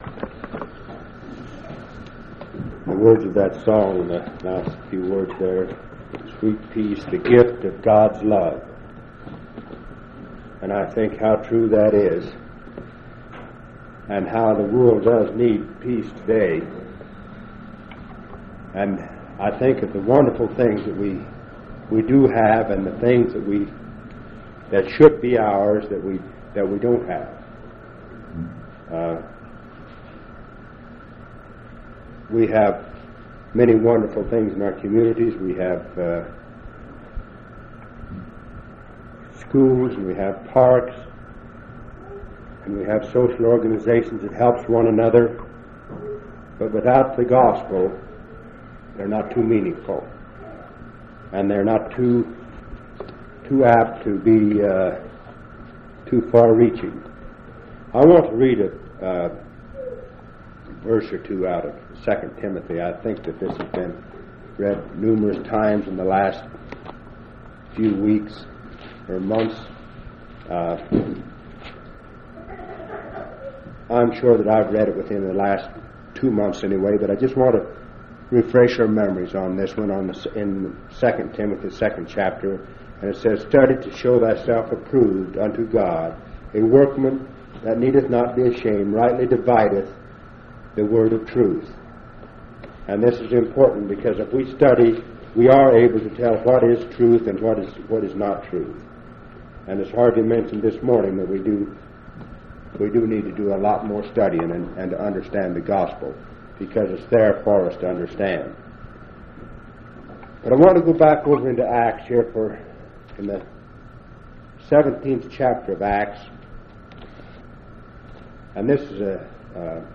7/24/1977 Location: Grand Junction Local Event